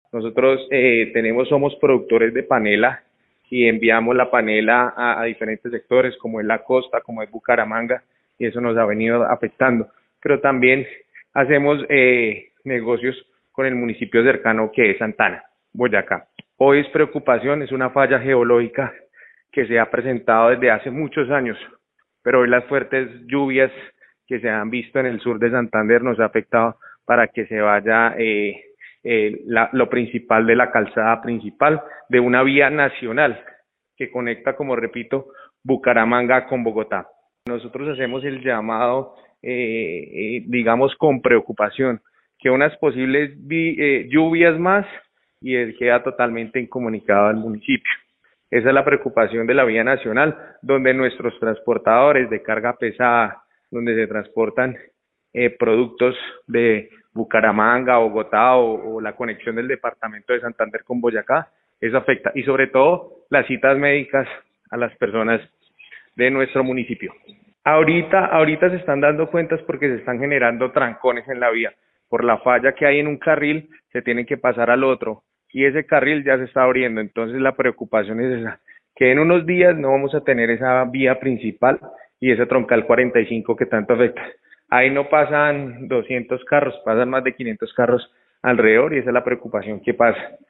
Alcalde de Güepsa, Hernando Fontecha